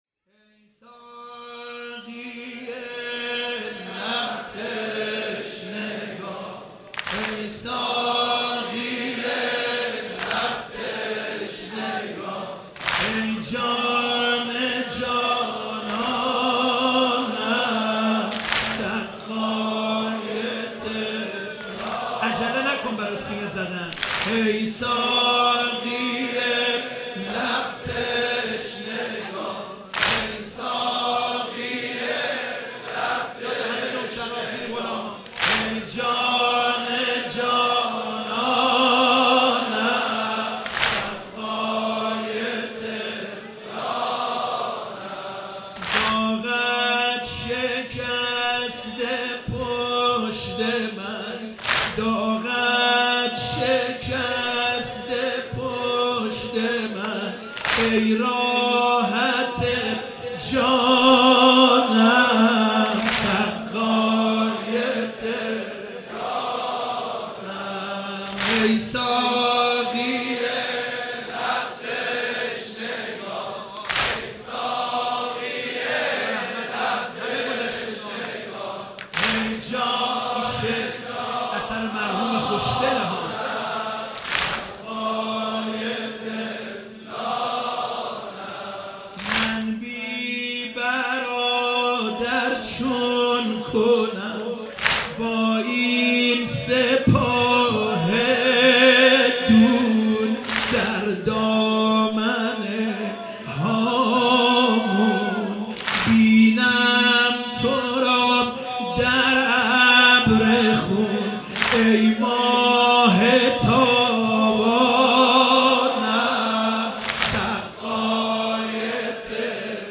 شب تاسوعا محرم 96 - واحد - ای ساقی لب تشنگان;